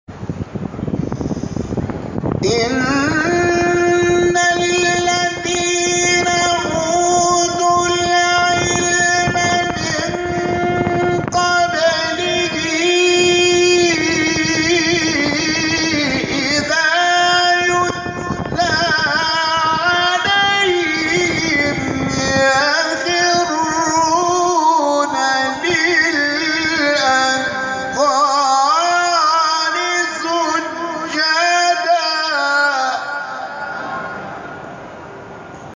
شبکه اجتماعی: فرازهای صوتی از تلاوت قاریان ممتاز کشور را می‌شنوید.